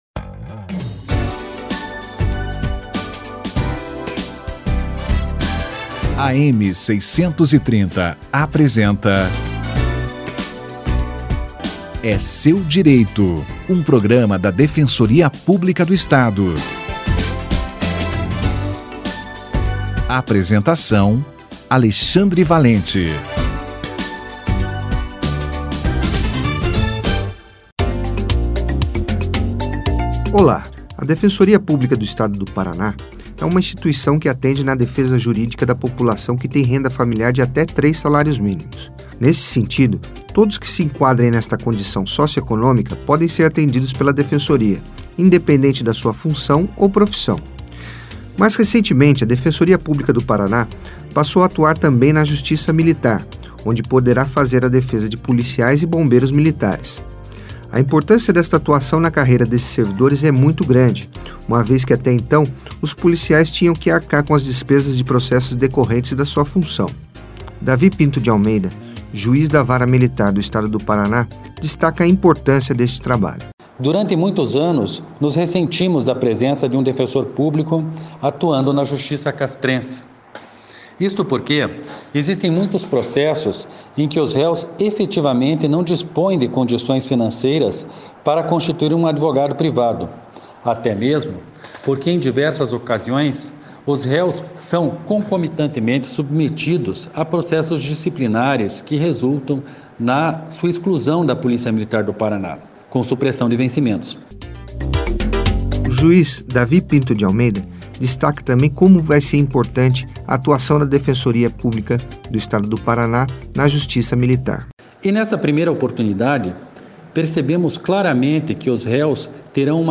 11/12/2017 - Atuação da Defensoria Pública na Justiça Militar - Entrevista com o juiz Davi Pinto de Almeida